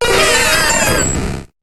Cri de Mew dans Pokémon HOME